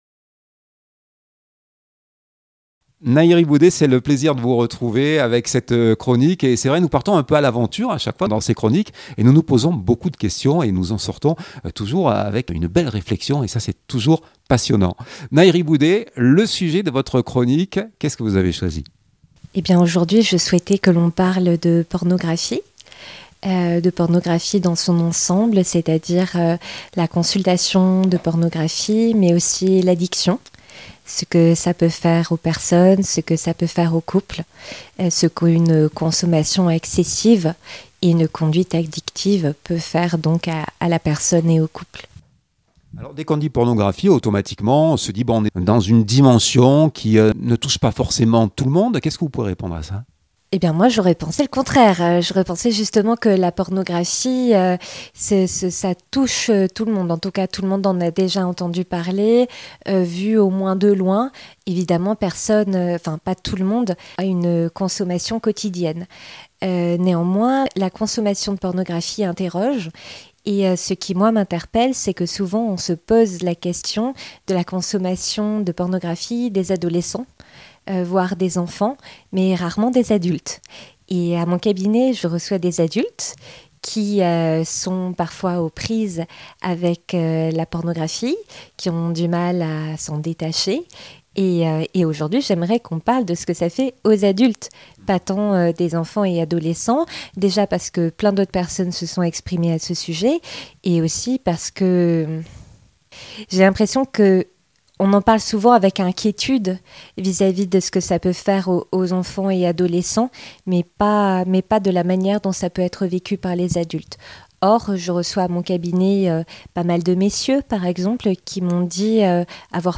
Chronique mensuelle